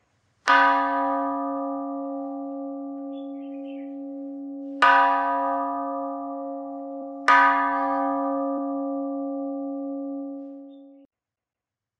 Tiếng Chuông Chùa (Âm thanh thực)
Thể loại: Tiếng chuông, còi
Description: Tiếng chuông chùa ngân vang liên hồi, âm thanh trầm ấm và vang vọng khắp không gian tĩnh lặng, gợi cảm giác linh thiêng và an yên đặc trưng của chốn cửa Phật. Âm vang của quả chuông cỡ vừa được gõ ba hồi liên tiếp, tiếng ngân lan xa, dội nhẹ vào núi đồi và không gian xung quanh, đậm chất Việt Nam, tạo nên một hiệu ứng âm thanh sống động, chân thực, thích hợp làm sound effect trong các dự án chỉnh sửa video, phim tư liệu hoặc cảnh quay về chùa chiền và thiền định.
tieng-chuong-chua-am-thanh-thuc-www_tiengdong_com.mp3